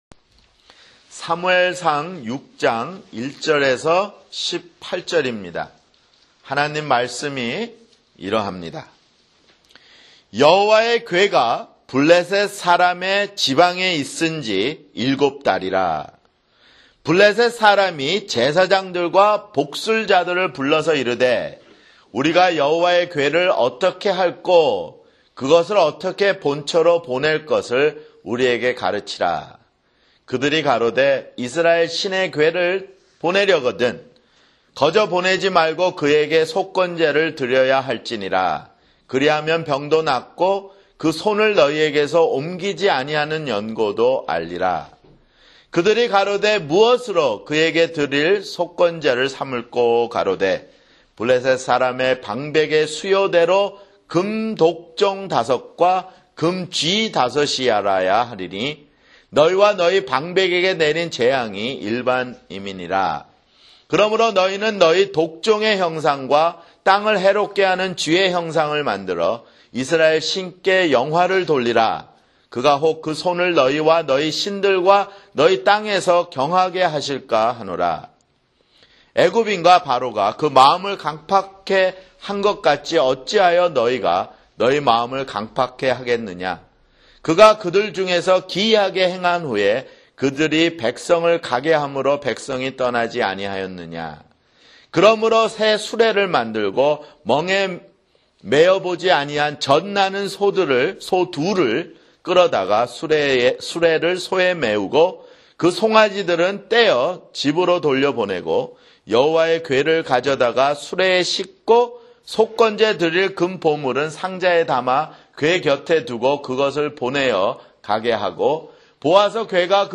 [주일설교] 사무엘상 (20)